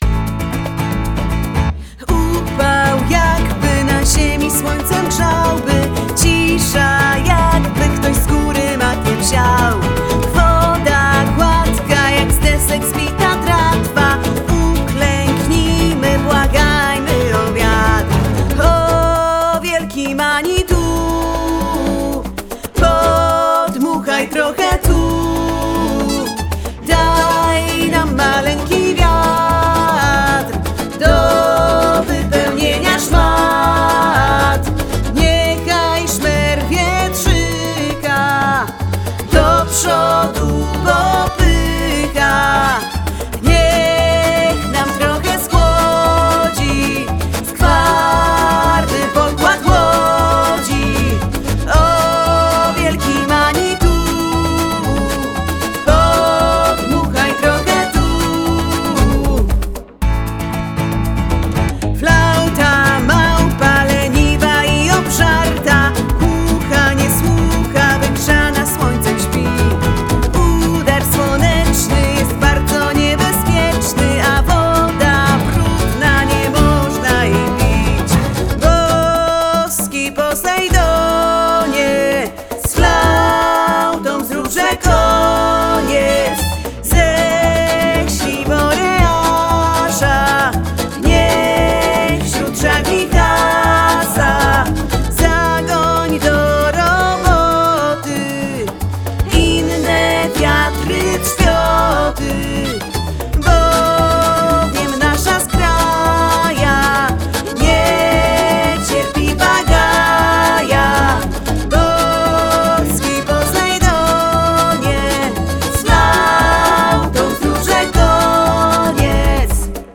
wokal